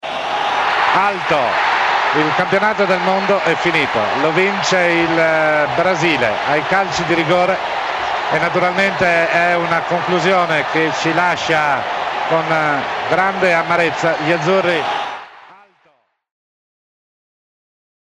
Brazil won, after Italian player Roberto Baggio missed the decisive penalty. Commentator Bruno Pizzul’s voice conveyed the sadness of the Italian fans, after the defeat.